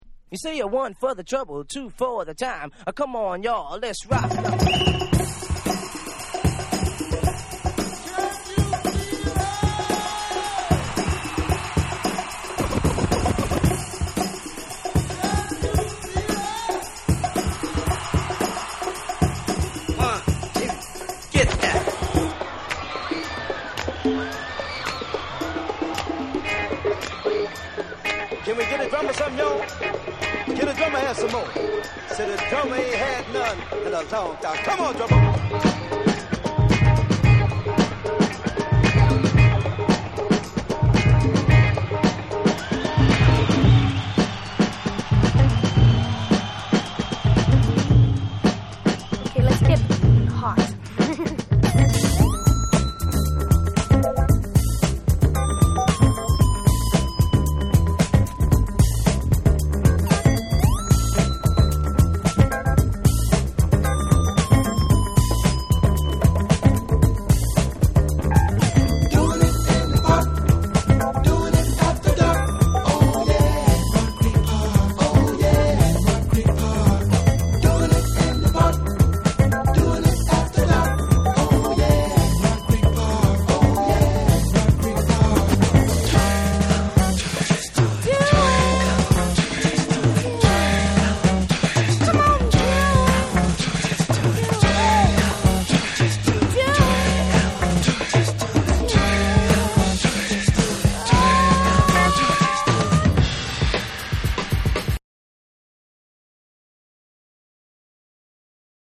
サンプリングで構成されたメガミックス・チューンを中心にカラフルなブレイクビーツ・ナンバーを収録。
JAPANESE / BREAKBEATS